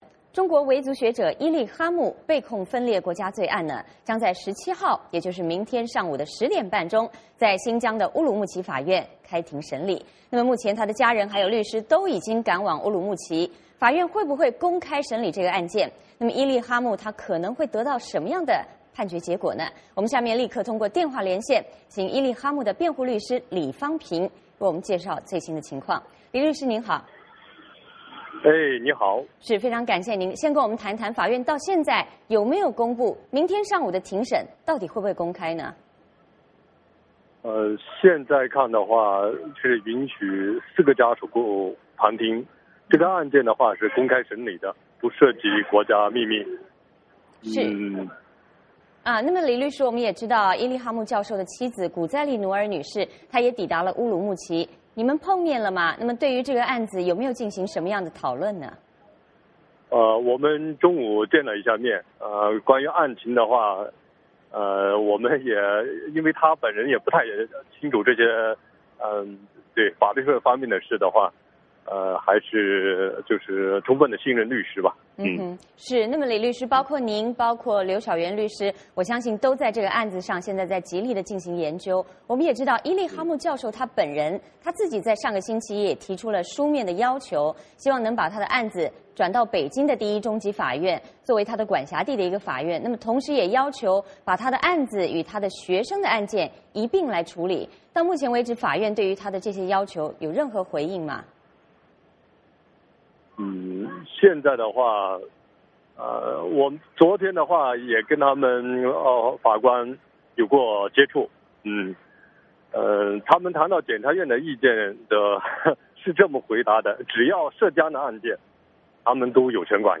VOA连线：伊力哈木案周三开审，全球聚焦乌鲁木齐